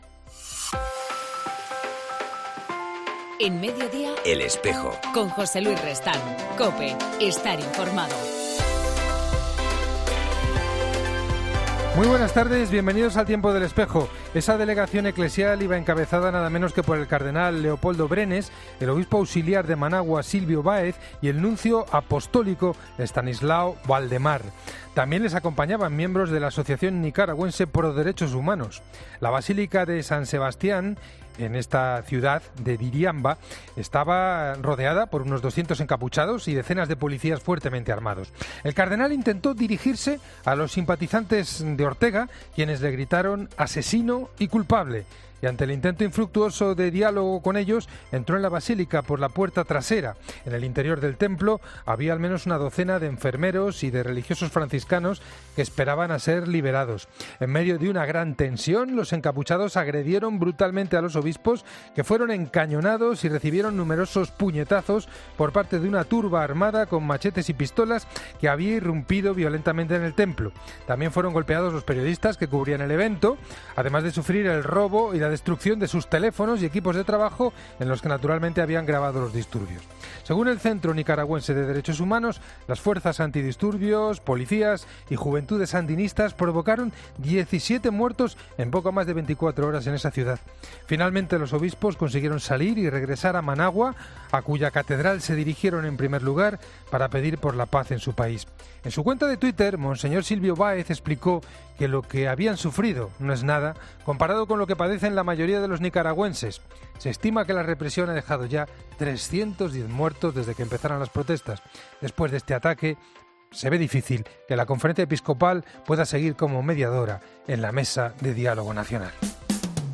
AUDIO: En El Espejo hablamos con